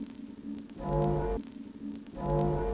Vinyl_Tone_Layer_05.wav